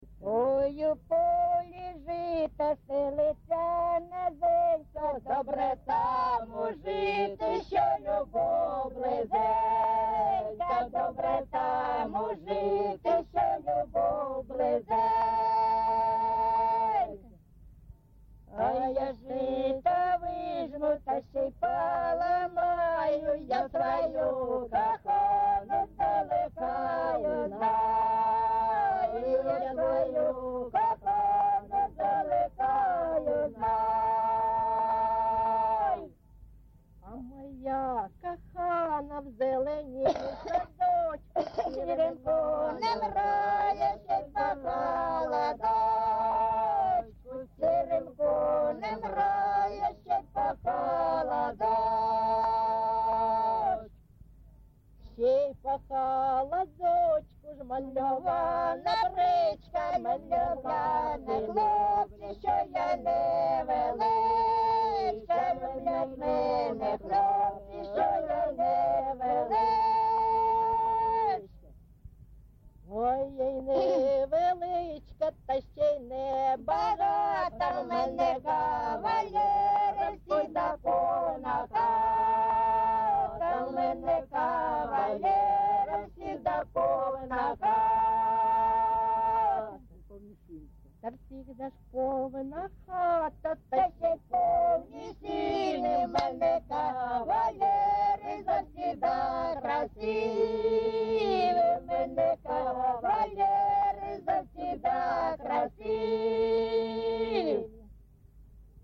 GenrePersonal and Family Life
Recording locationTorske, Krasnolymansky district, Donetsk obl., Ukraine, Sloboda Ukraine